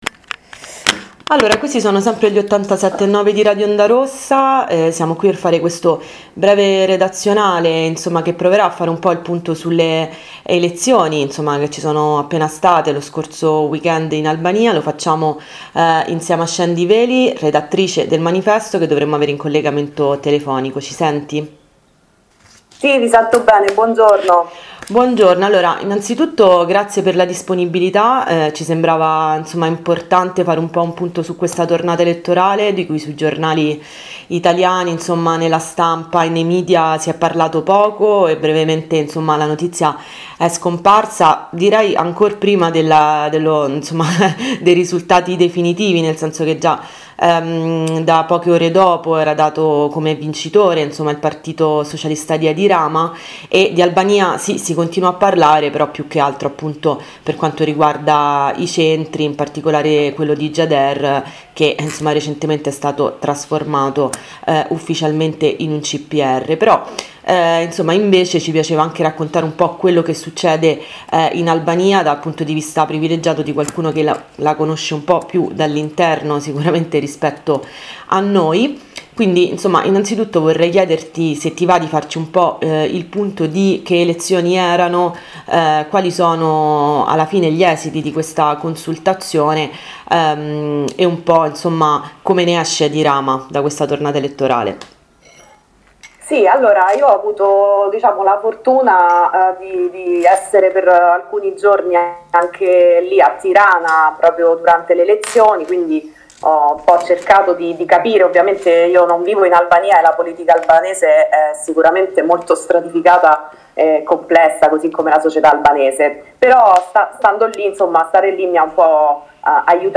ROR intervista Albania.MP3